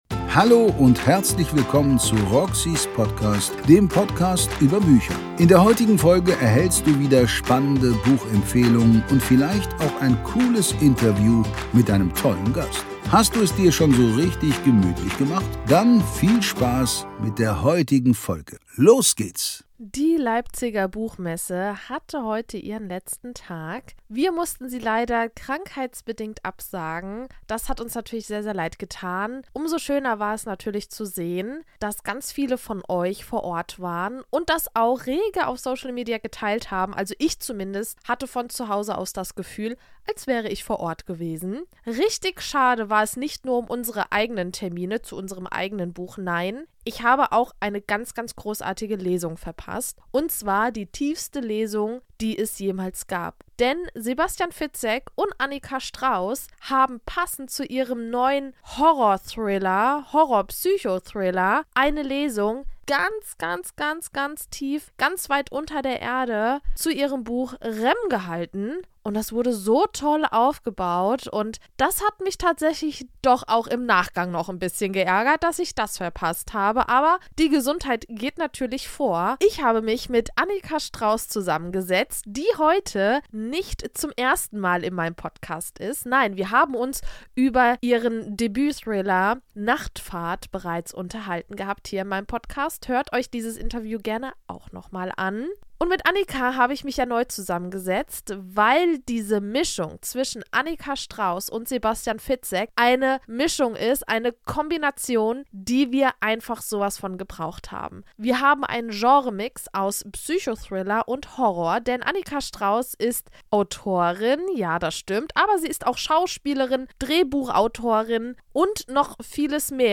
Folge #312 REM inkl. Interview